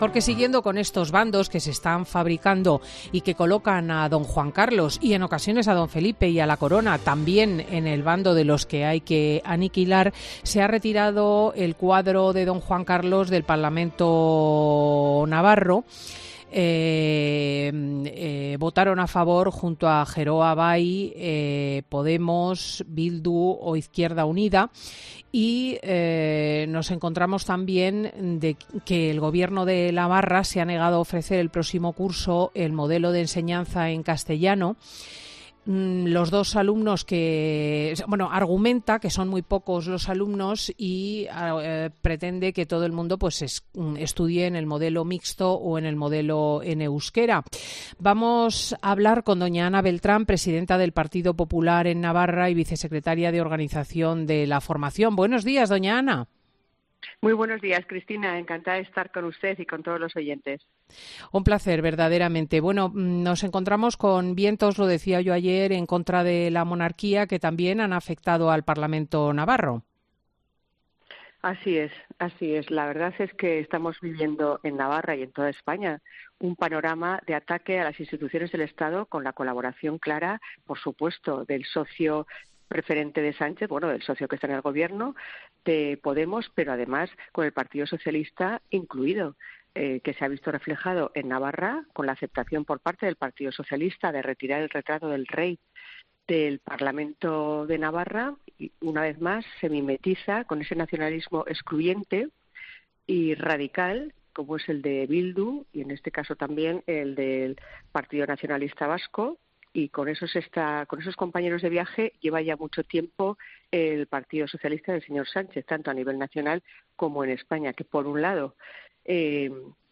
La diputada del PP, Ana Beltrán, critica en Fin de Semana la retirada del retrato del rey emérito del parlamento navarro y lo considera un...